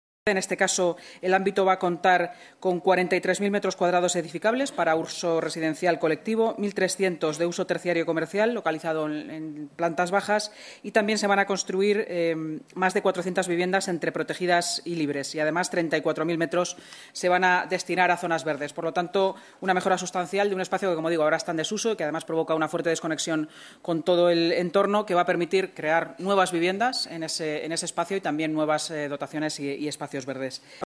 Nueva ventana:Intervención de la vicealcaldesa y portavoz municipal, Inma Sanz